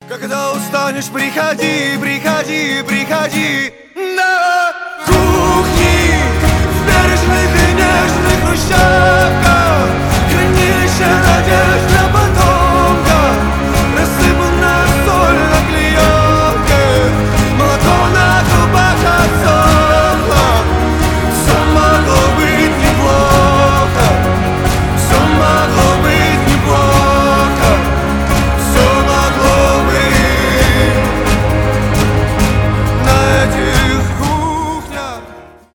indie rock
альтернатива
душевные